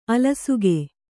♪ alasuge